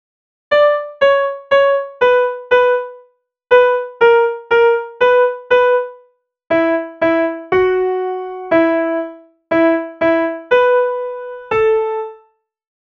Portion of Blue Danube Waltz with Lots of Discontinuous Rests
The MIDI values for most of those notes are in the 80s, and each movement to a rest and from a rest results in an 80+ data point.